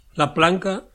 Prononciation occitane : La Planque 00:00 / 00:00 Sommaire